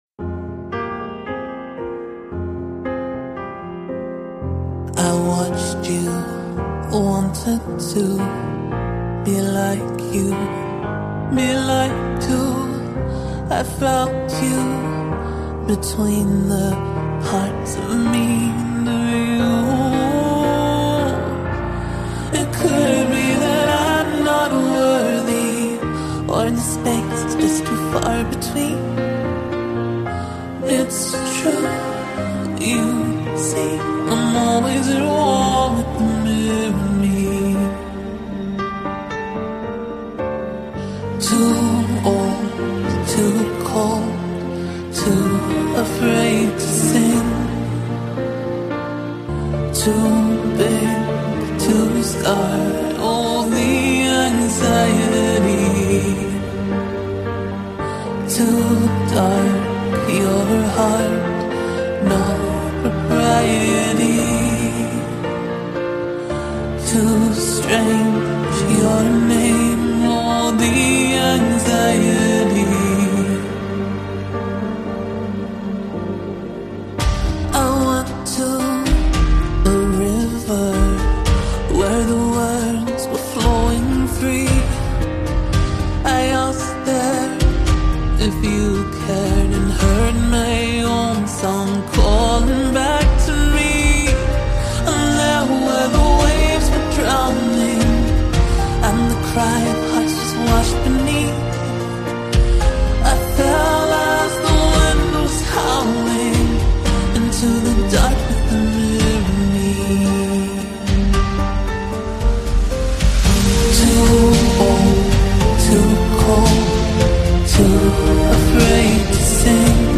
Adult Contemporary